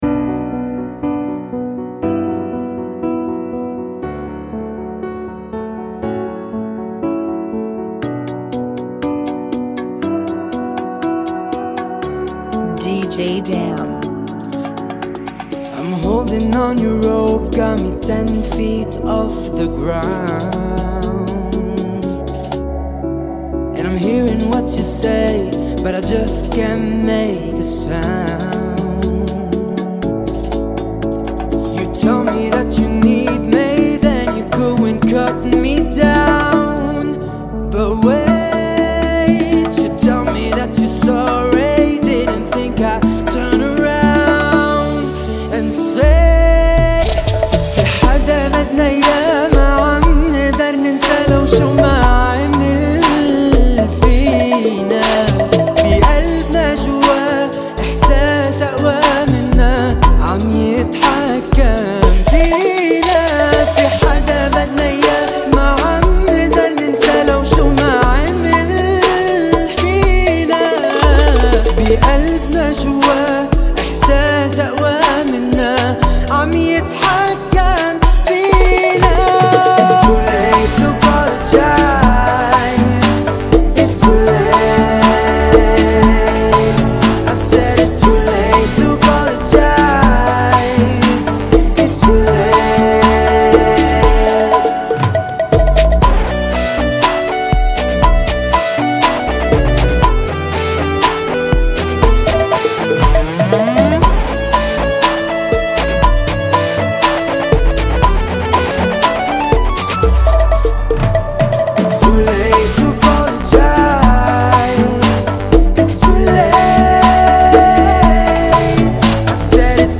120 BPM
Genre: Bachata Remix